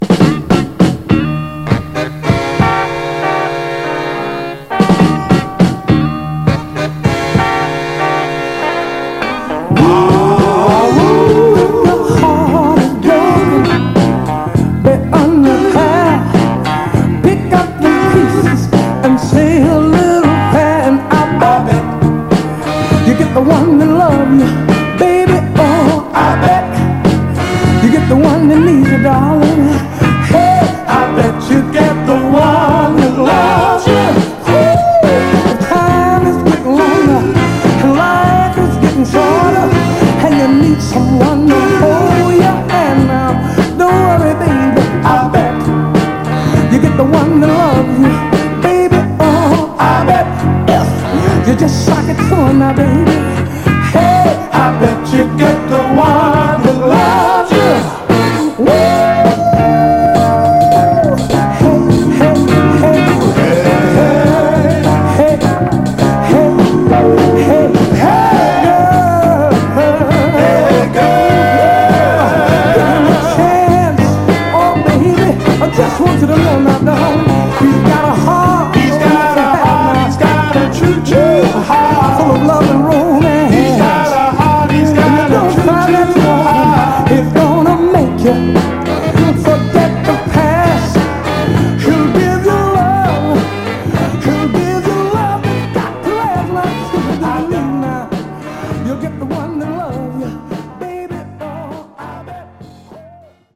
初期らしいメンフィス・スタイルを取り入れたロウなファンク・サウンドがカッコいい、ミッドなデトロイト・ソウルです！
※試聴音源は実際にお送りする商品から録音したものです※